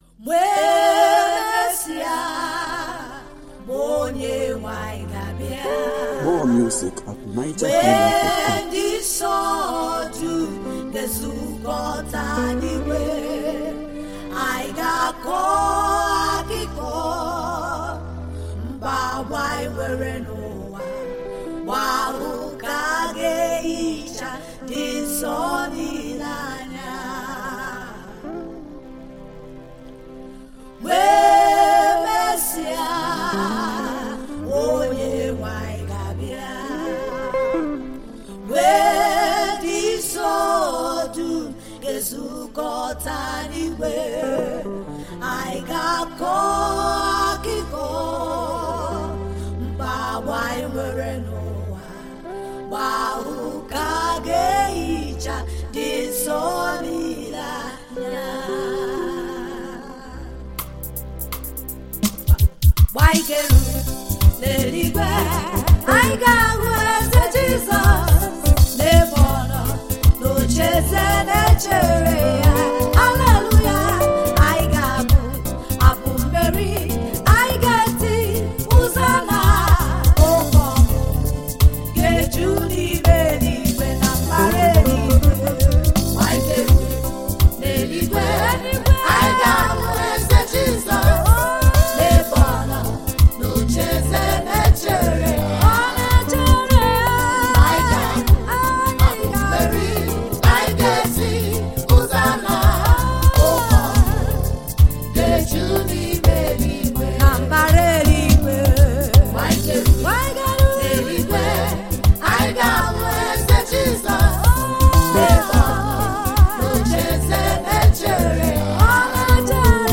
Igbo gospel song